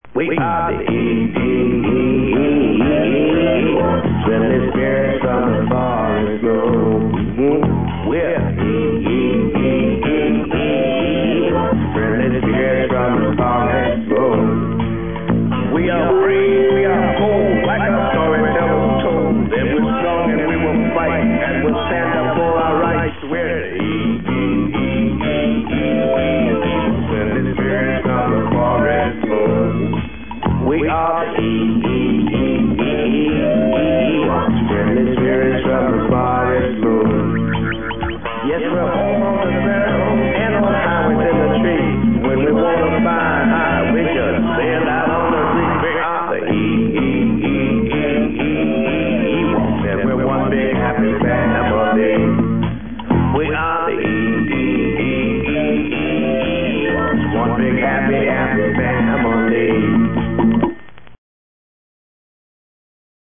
theme music